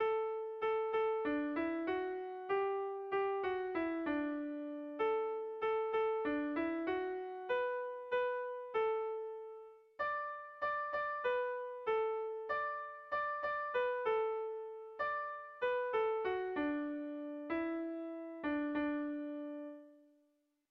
Kontakizunezkoa
Lauko handia (hg) / Bi puntuko handia (ip)
AB